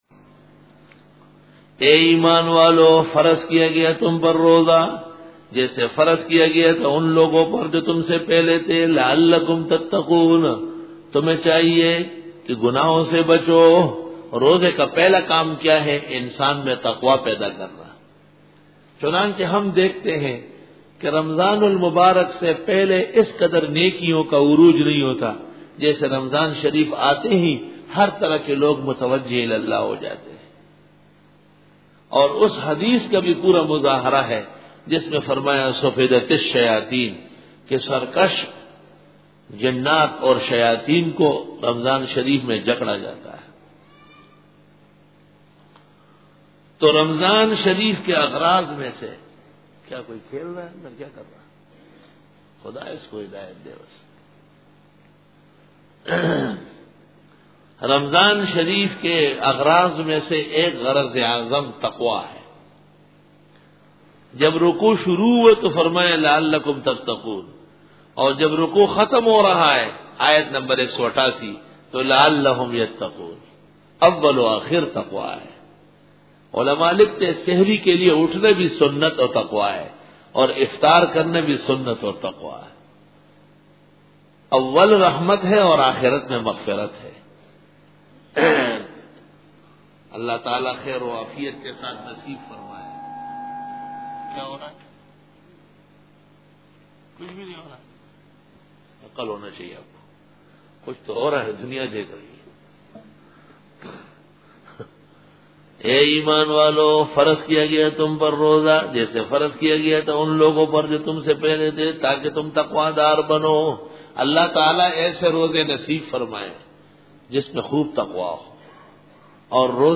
سورۃ البقرۃ رکوع-23 Bayan